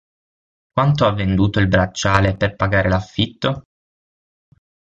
/bratˈt͡ʃa.le/